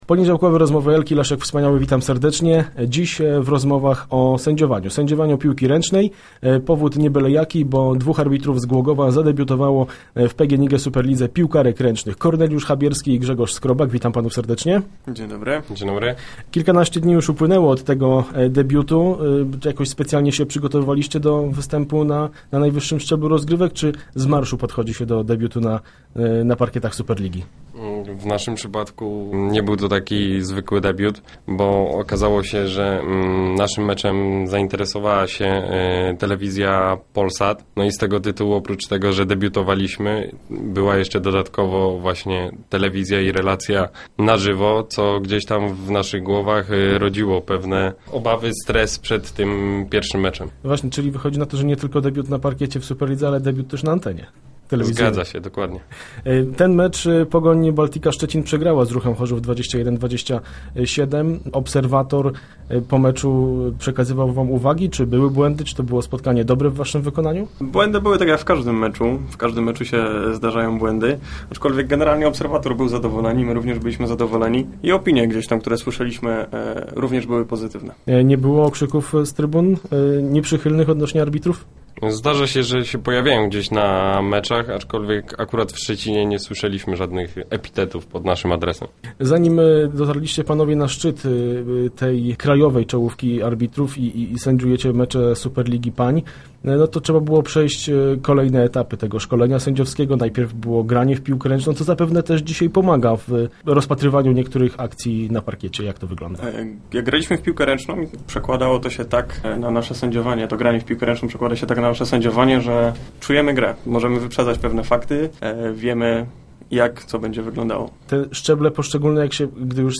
Start arrow Rozmowy Elki arrow Zadebiutowali w Superlidze